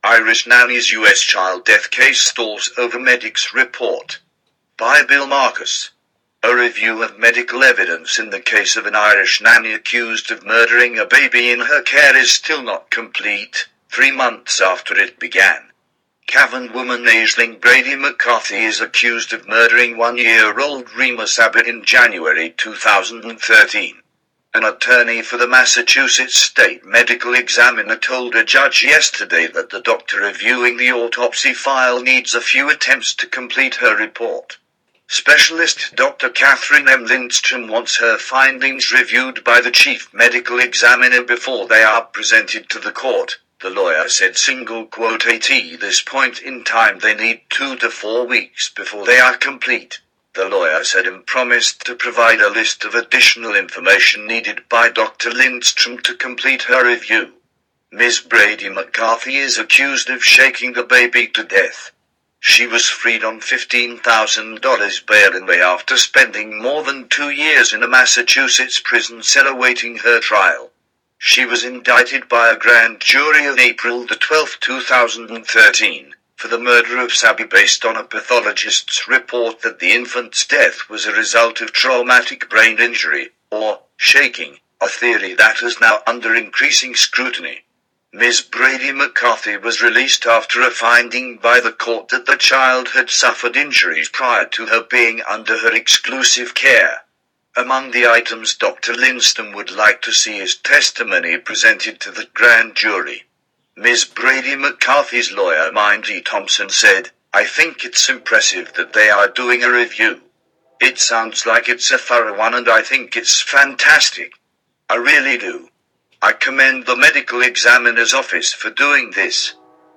IRISH-DAILY-MAIL-ARTICLE-OF-JULY-31-READ-BY-A-COMPUTER-WITH-AN-IRISH-ACCENT.mp3